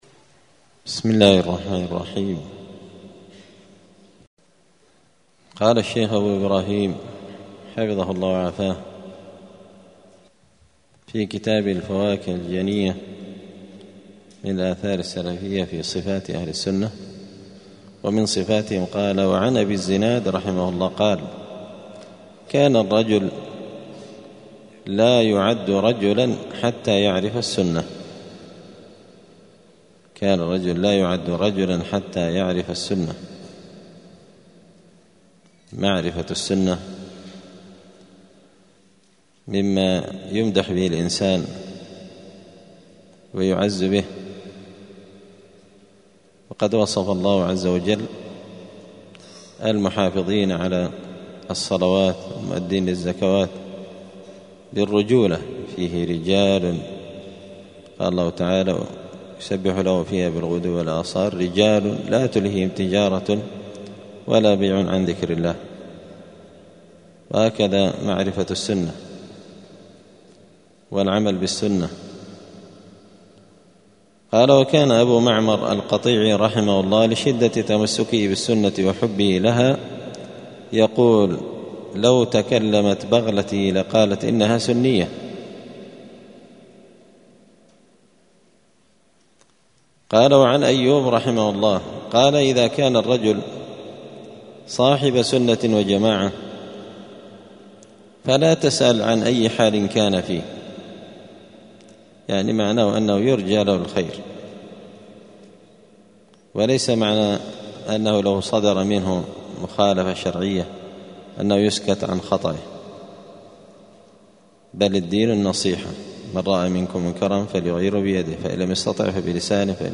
دار الحديث السلفية بمسجد الفرقان بقشن المهرة اليمن
الأثنين 22 صفر 1446 هــــ | الدروس، الفواكه الجنية من الآثار السلفية، دروس الآداب | شارك بتعليقك | 32 المشاهدات